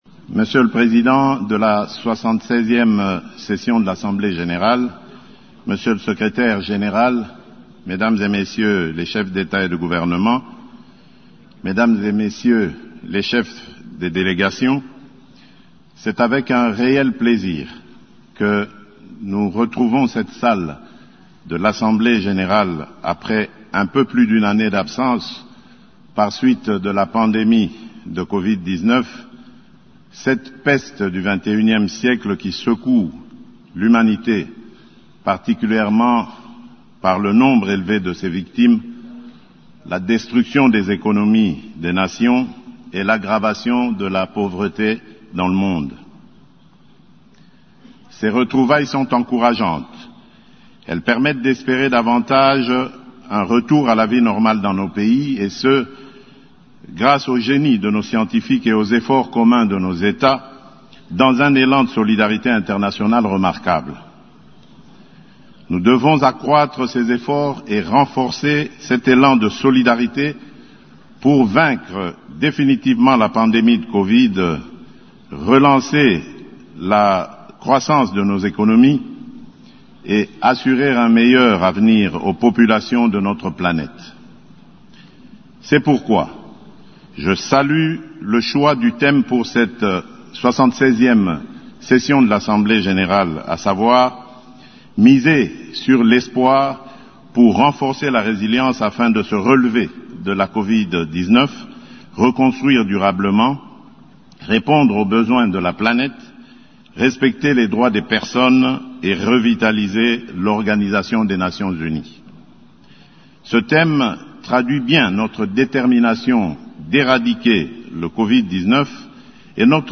A l’occasion de son adresse mardi 21 septembre du haut de la tribune de l’assemblée générale de l’ONU, il estime que le nombre de vaccins à distribuer dépassera les 25 millions de doses par mois d’ici à janvier 2022.